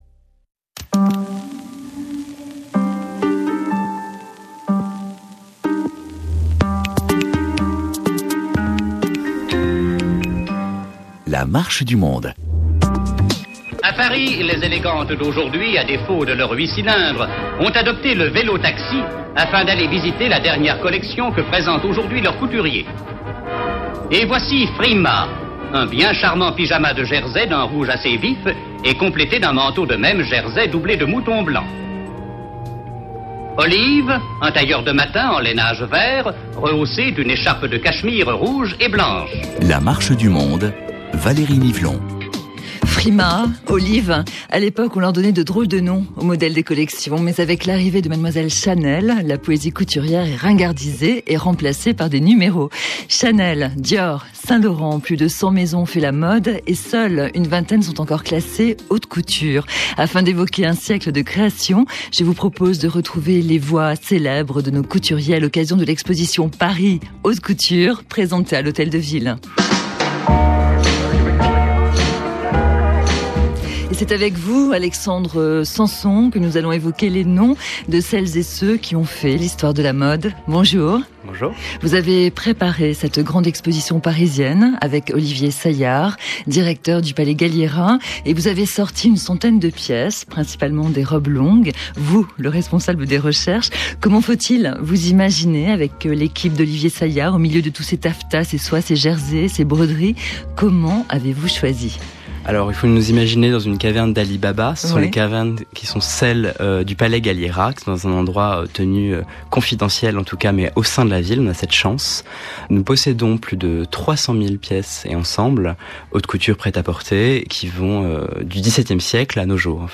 A l'aide de cette interview d'un célèbre couturier, apprenez à vos étudiants à parler d'une collection haut de de gamme.